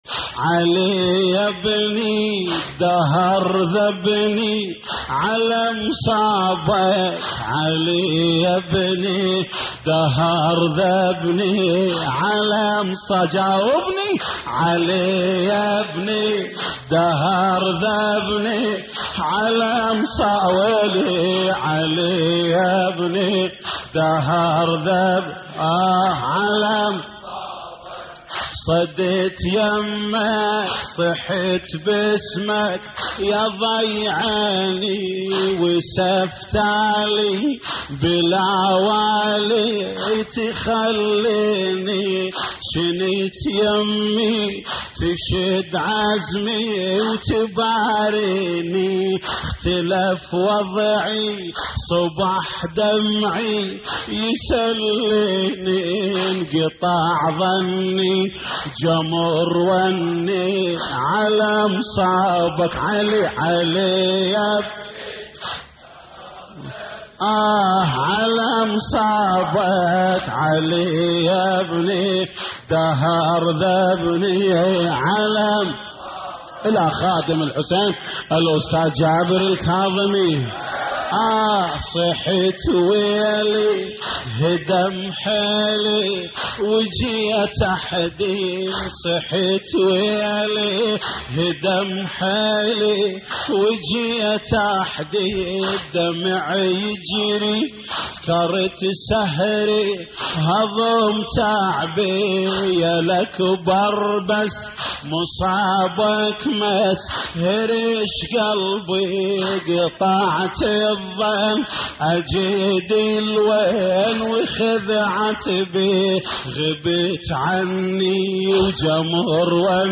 تحميل : علي يبني الدهر ذبني على مصابك / الرادود جليل الكربلائي / اللطميات الحسينية / موقع يا حسين